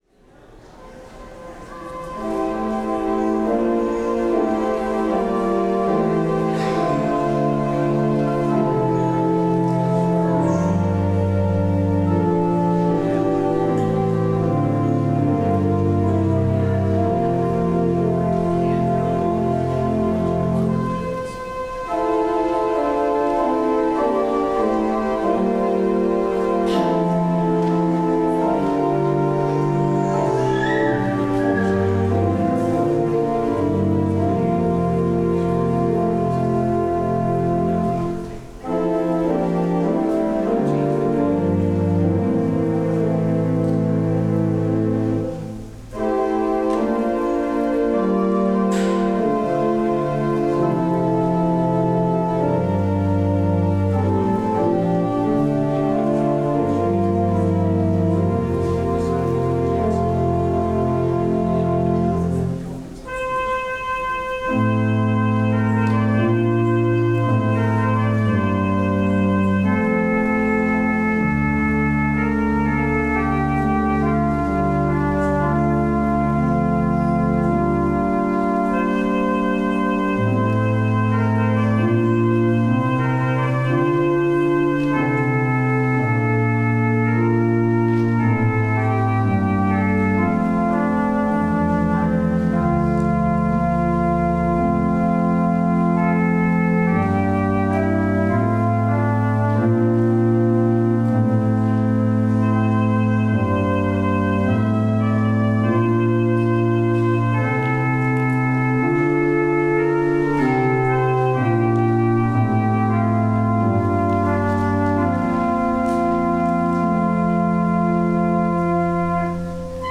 Service of Worship